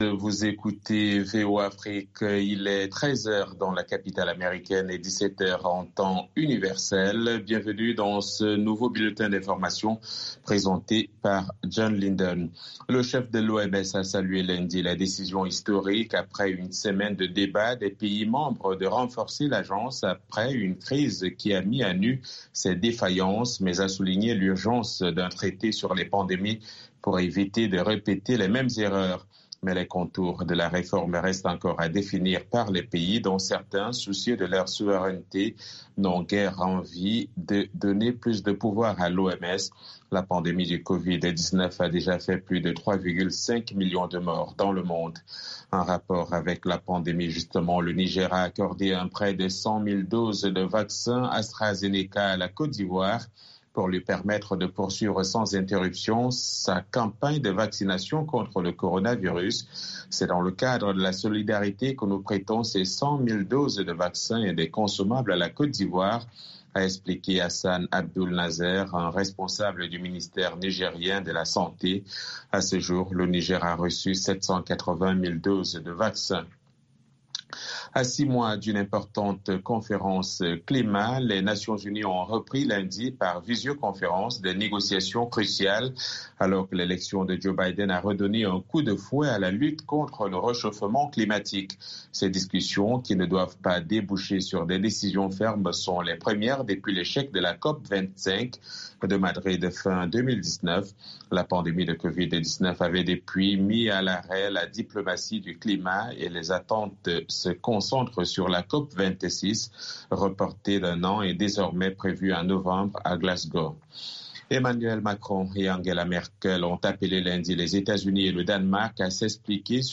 10 min Newscast